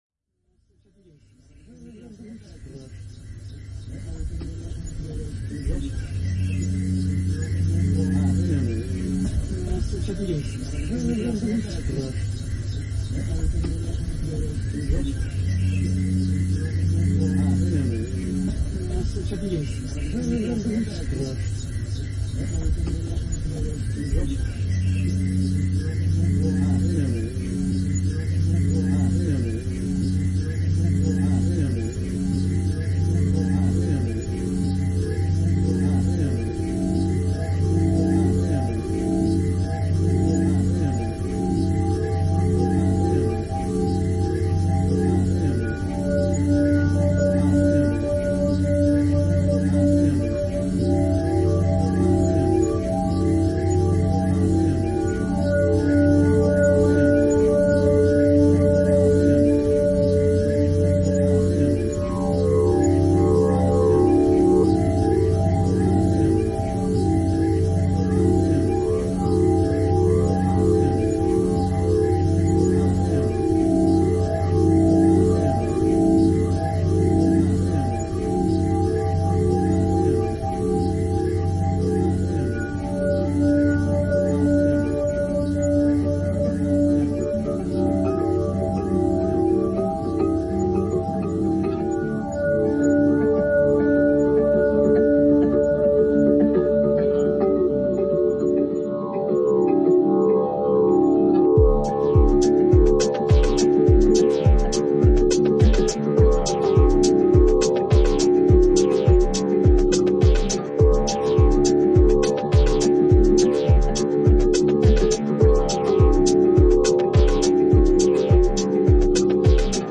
• Recorded live @ Riserva San Settimio
synth modular
Sax
natural sound base noise